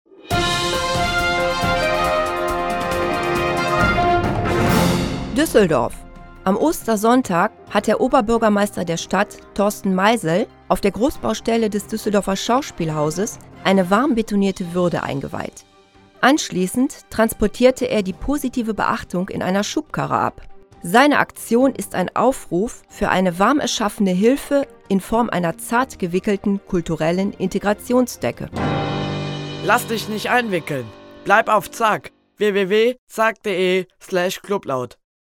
Spot-II-Fakenews-Sprecher-weiblich-Slogan-maennlich.mp3